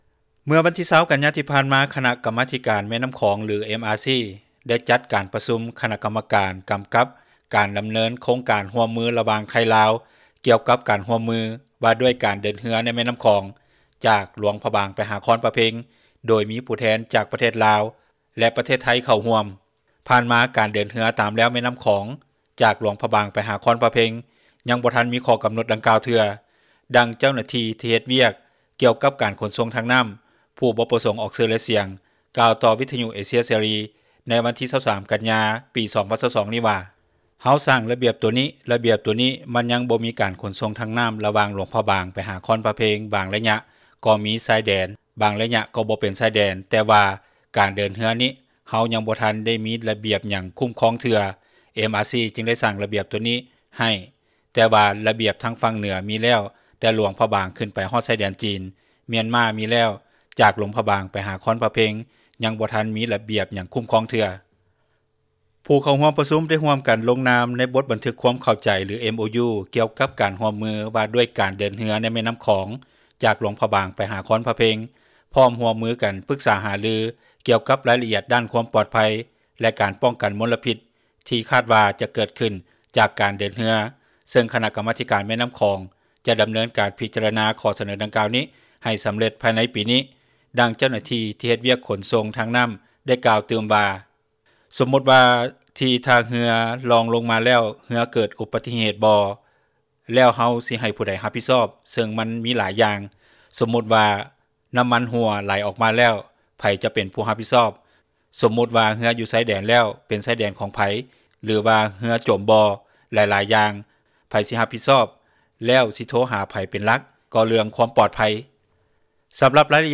ດັ່ງເຈົ້າໜ້າທີ່ ທີ່ເຮັດວຽກຂົນສົ່ງ ທາງນໍ້າກ່າວຕື່ມວ່າ: